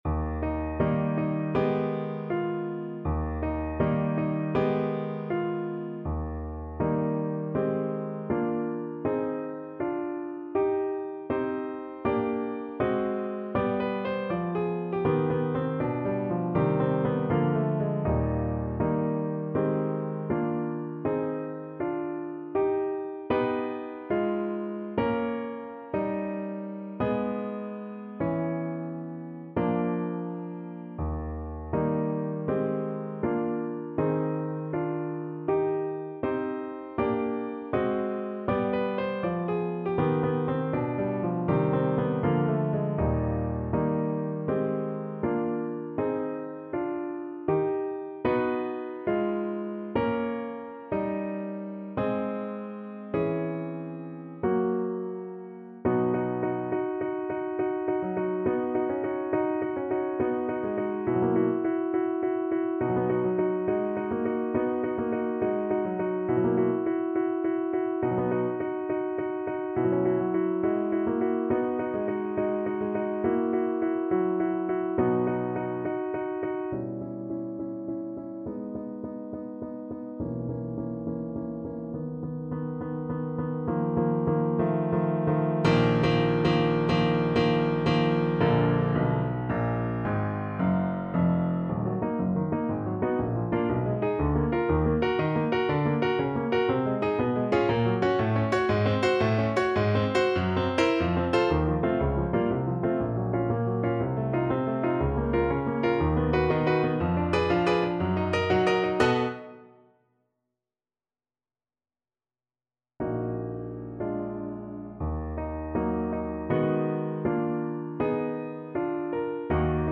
2/4 (View more 2/4 Music)
Moderato =80
Classical (View more Classical French Horn Music)